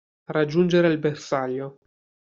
ber‧sà‧glio
/berˈsaʎ.ʎo/